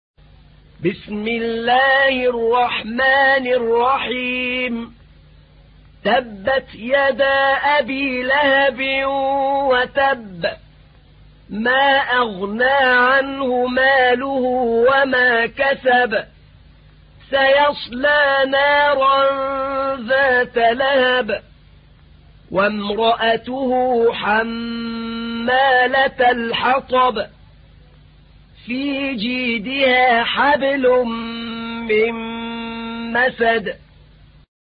تحميل : 111. سورة المسد / القارئ أحمد نعينع / القرآن الكريم / موقع يا حسين